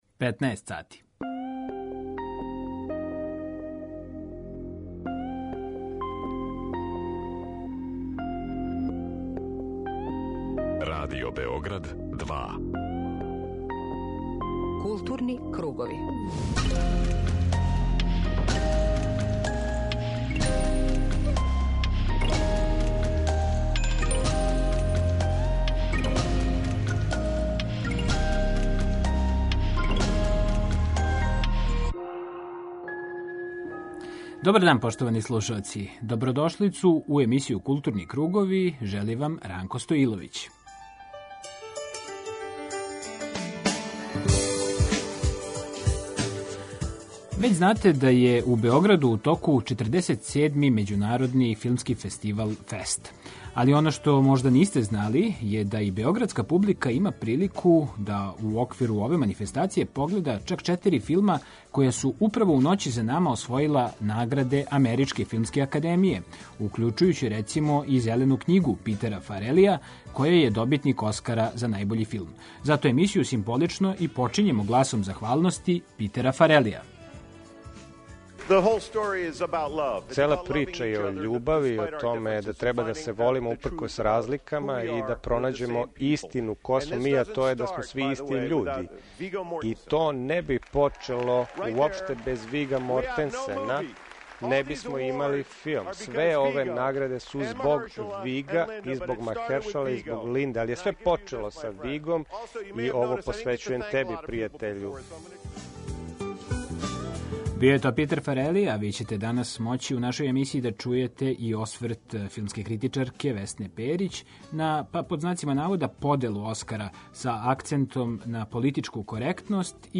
Дневни магазин културе Радио Београда 2
Осврнућемо се на прве дане 47. Међународног филмског фестивала - ФЕСТ 2019. Новинари и филмски критичари нашег програма пренеће нам своје утиске, разговоре са организаторима и гостима ФЕСТ-а. Представићемо и нову монографију посвећену раду Предрага Пеђе Нешковића „Слобода најјачем", коју је приредио историчар уметности Јерко Денегри, a објављенa je у у издању „Вујичић колекције".